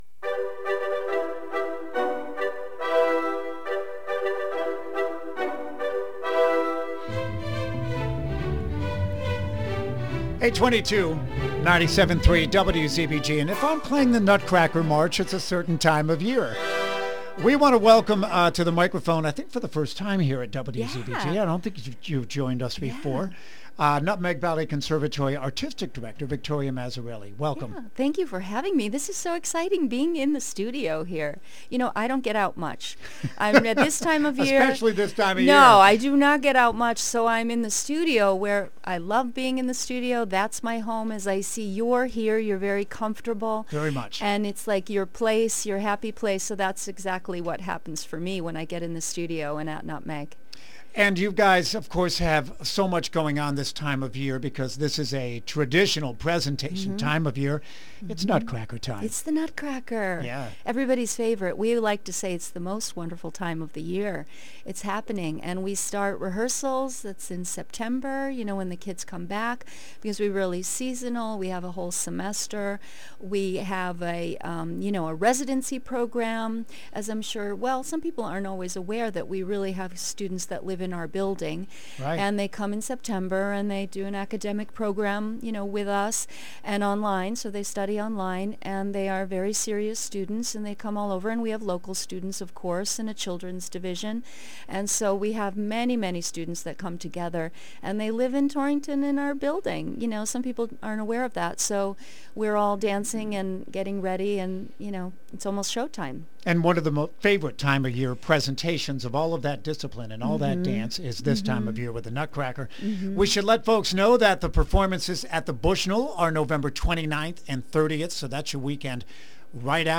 nutmeg-conservatory-intvu.mp3